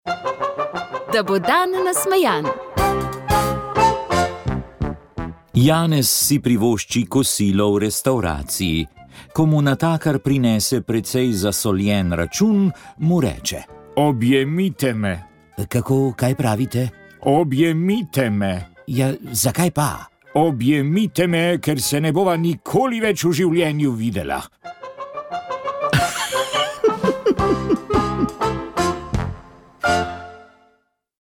V Šentjoštu je konec septembra potekala okrogla miza, na kateri so sodelujoči referenti razmišljali o 80 letnici konca druge svetovne vojne in komunističnem prevzemu oblasti.